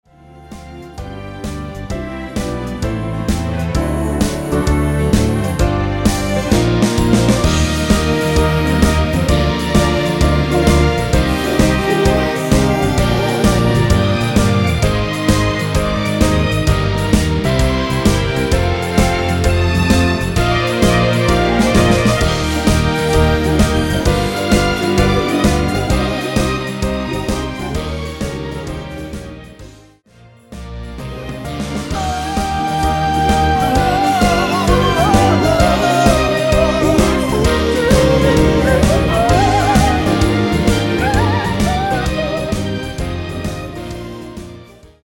전주 없는 곡이라 전주 2마디 만들어 놓았습니다.
엔딩이 페이드 아웃이라 라이브 하시기 편하게 엔딩을 만들어 놓았습니다.